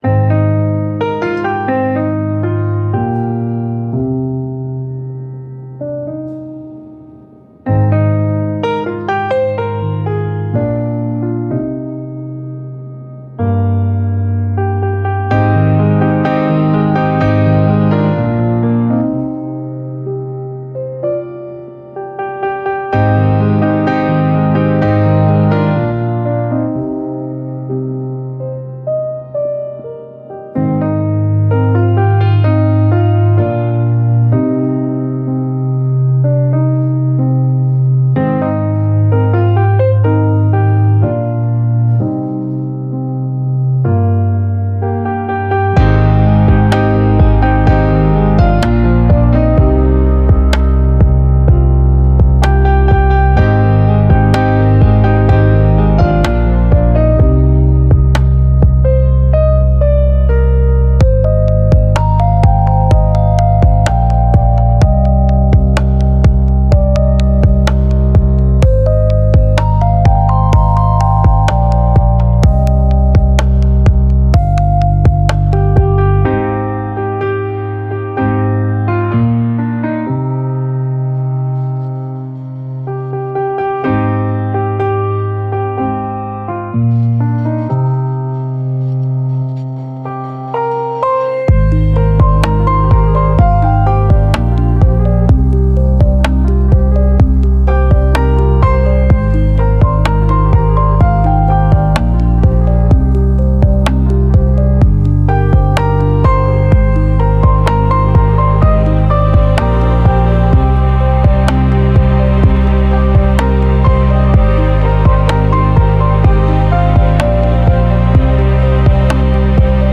Genre Melodic